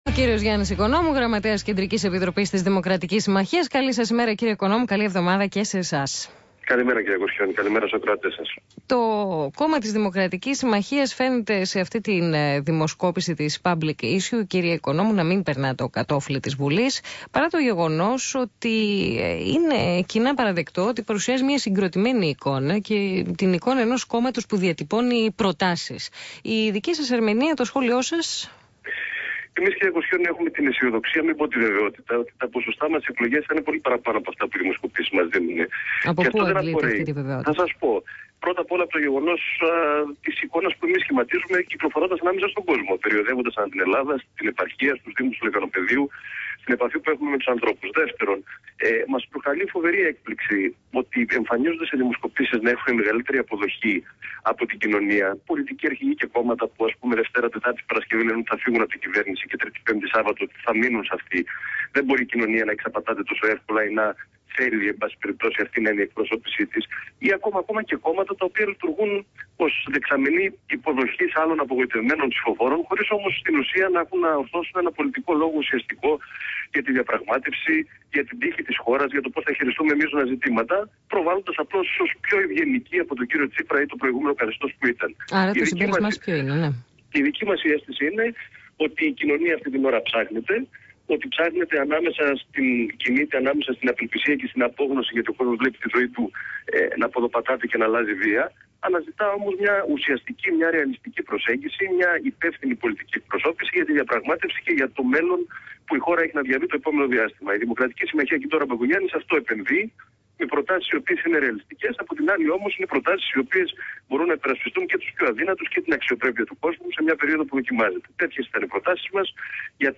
Ακούστε τη συνέντευξη του γραμματέα της Κεντρικής Επιτροπής του κινήματος στο ραδιόφωνο ΣΚΑΪ 100.3 και στη δημοσιογράφο Σία Κοσιώνη.